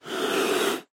Шипящий звук пантеры